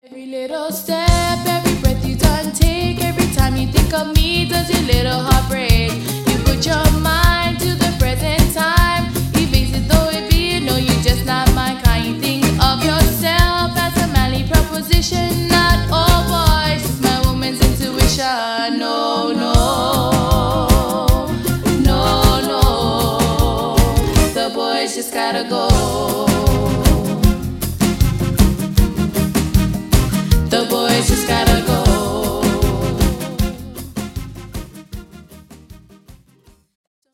melodic Island rhythms, fused to blues and soul